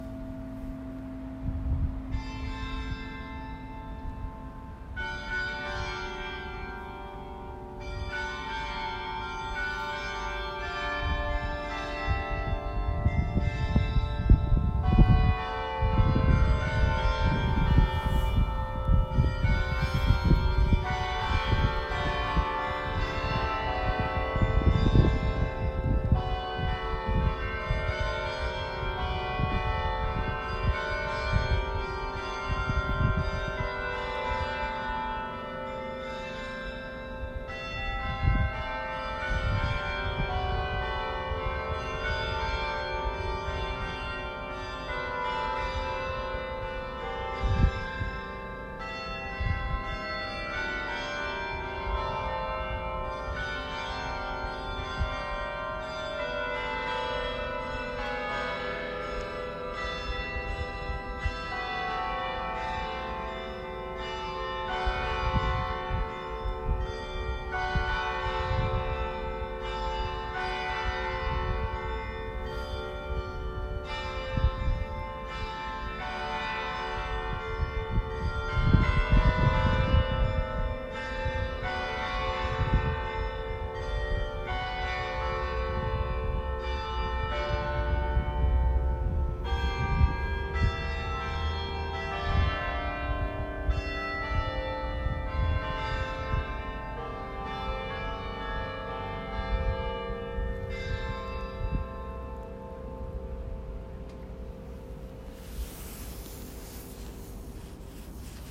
Klokkespill